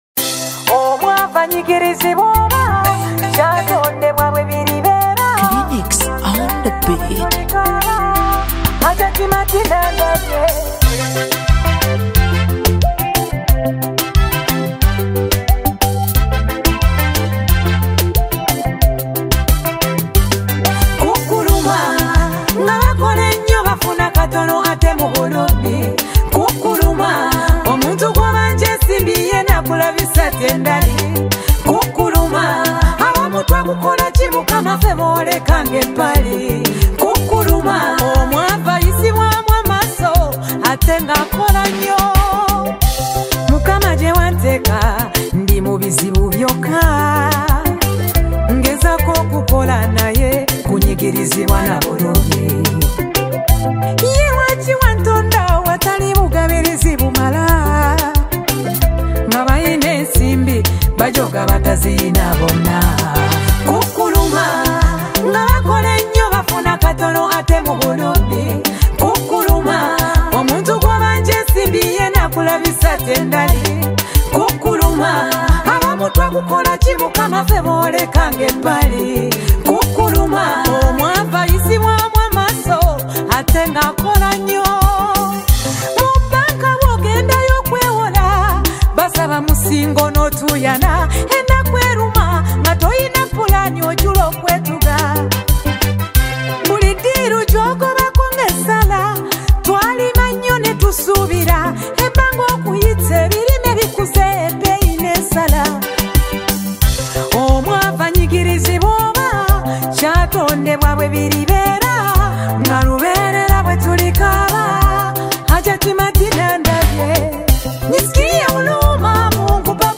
Genre: Kadongo Kamu